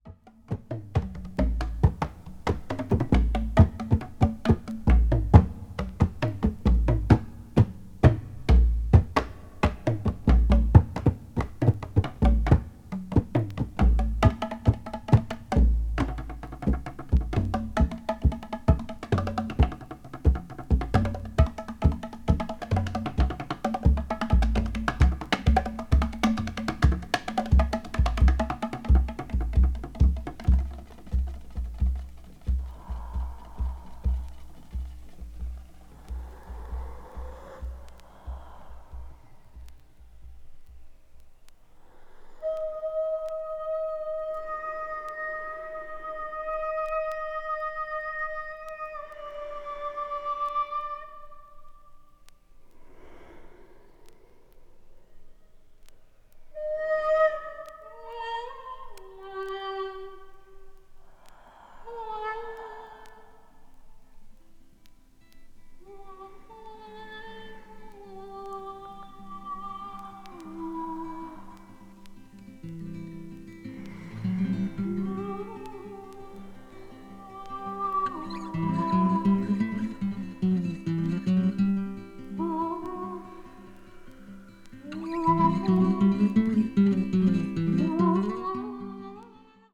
acid folk   brazil   mpb   psychedelic   world music